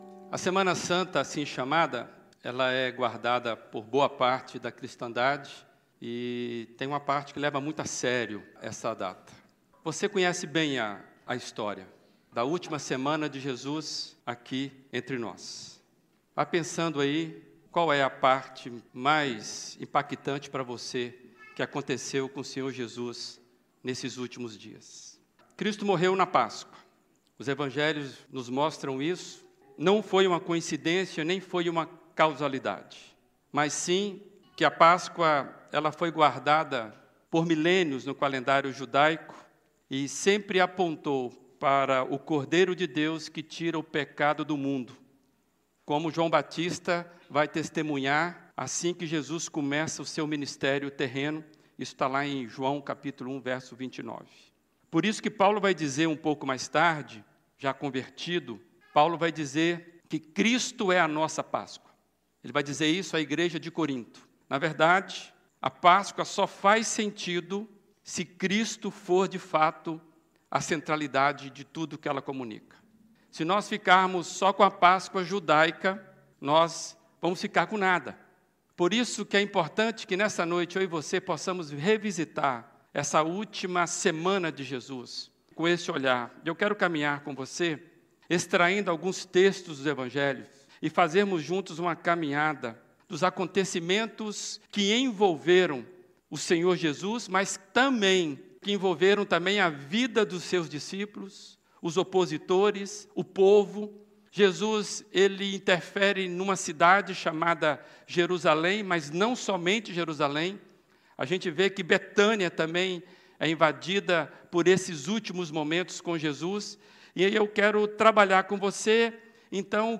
Mensagem
na Primeira Igreja Batista de Brusque